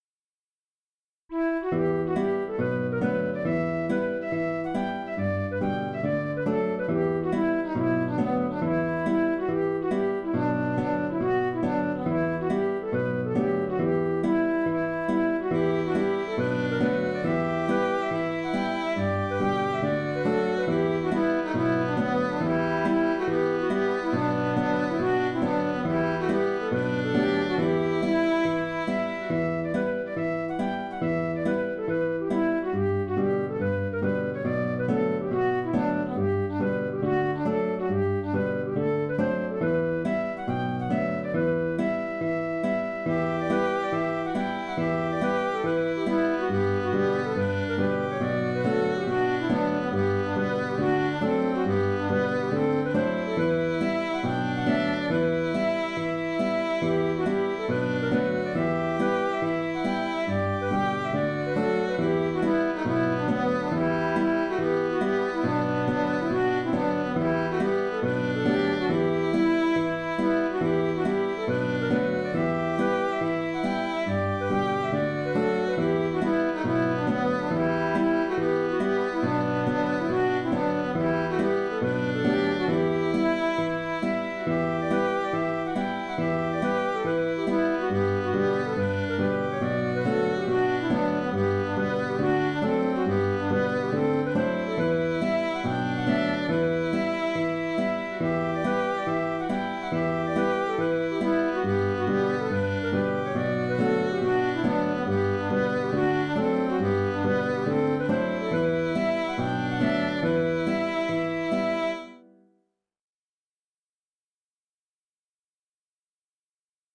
Galway Bay en Em (Hornpipe) - Musique irlandaise et écossaise
La mélodie de ce morceau a une originalité avec ses quelques notes étrangères à la gamme.
Auteur : Trad. Irlande.